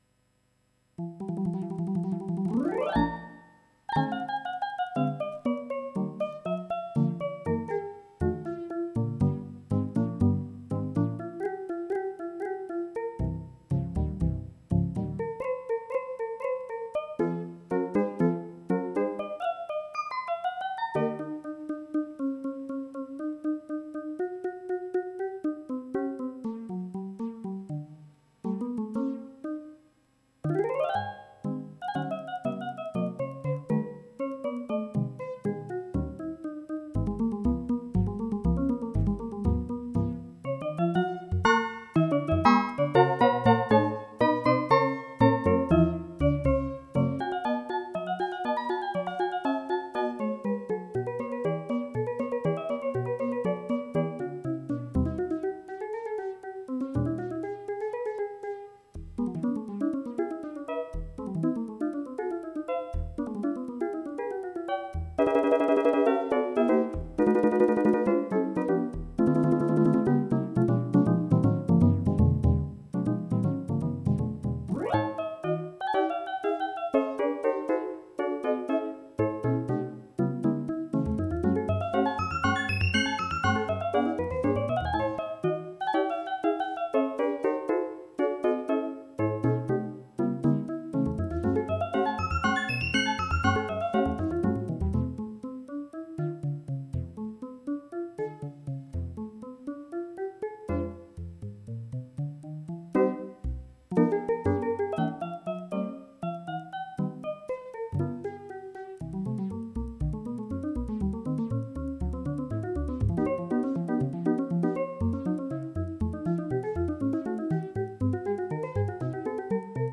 Toy Piano